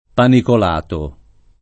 panicolato [ panikol # to ]